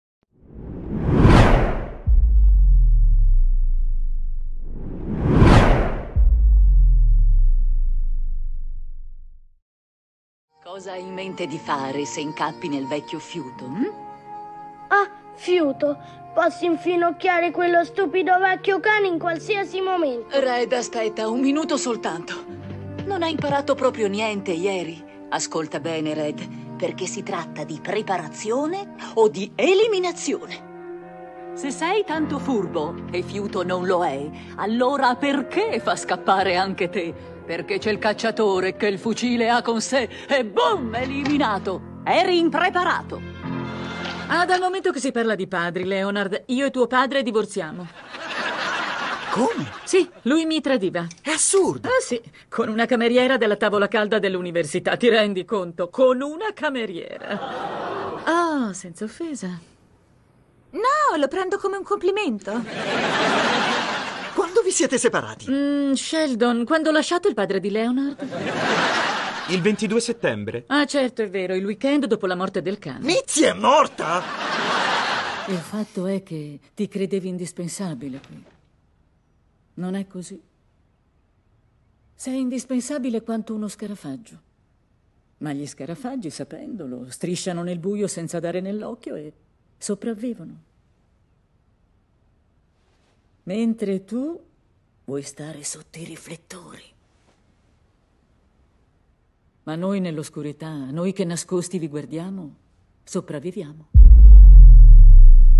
nel film d'animazione "Red e Toby nemiciamici", in cui doppia Gran Ma', nel telefilm "The Big Bang Theory", in cui doppia Christine Baranski, e nel film "Babylon", in cui doppia Jean Smart.